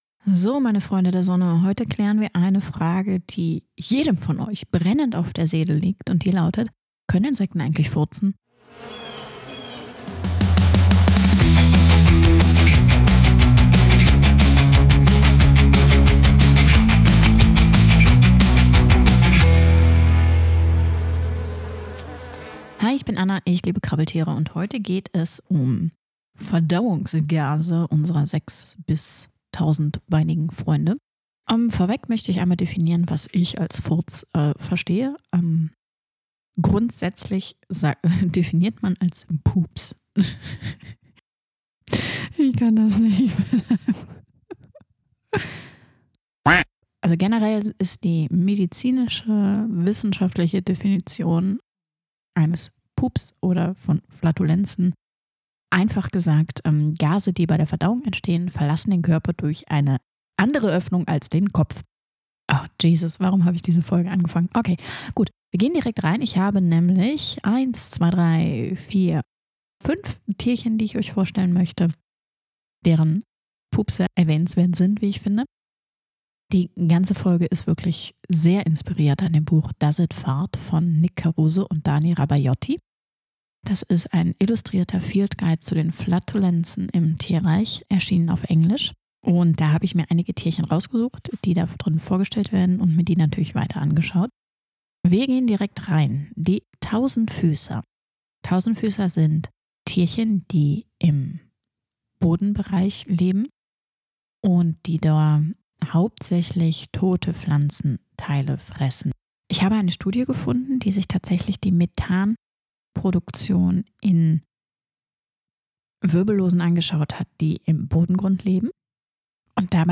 Intro Musik von Algorithmic Audiospace.